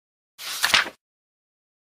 На этой странице собраны натуральные звуки перелистывания бумажных страниц.
Звук перелистывания страницы для видеомонтажа